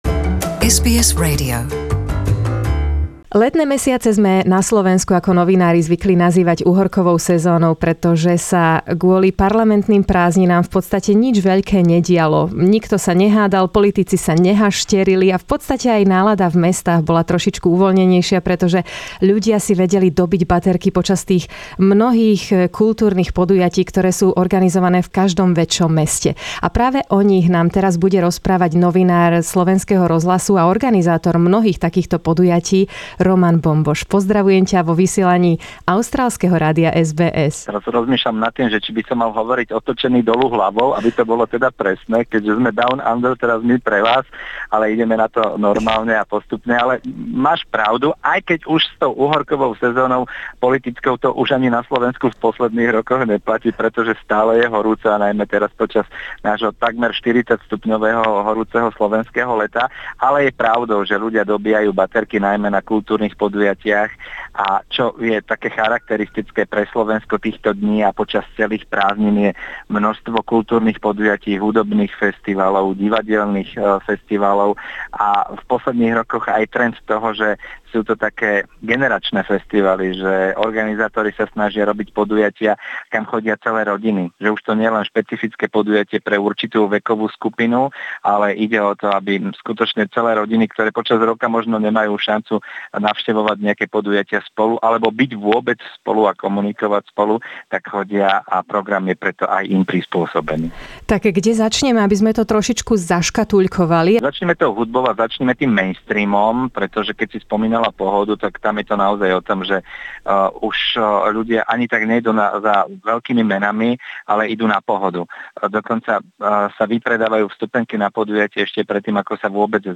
vypočujte si rozhovor s novinárom a organizátorom kultúrnych podujatí